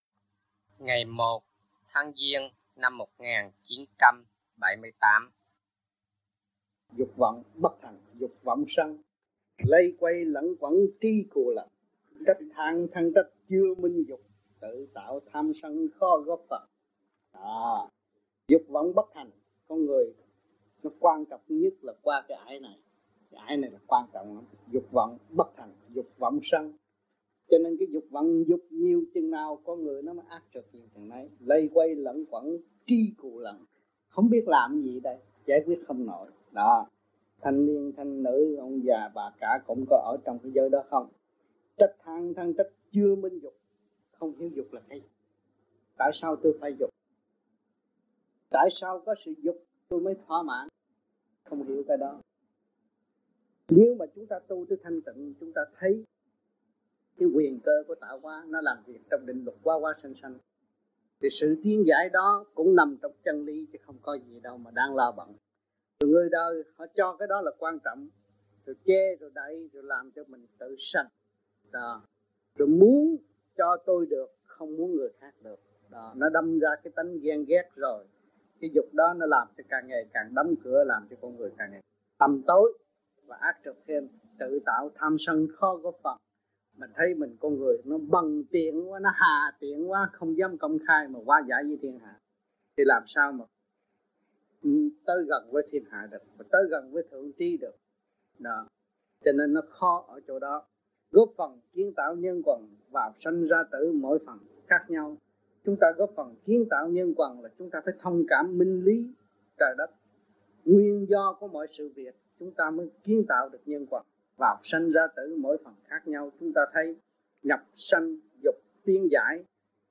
THUYẾT GIẢNG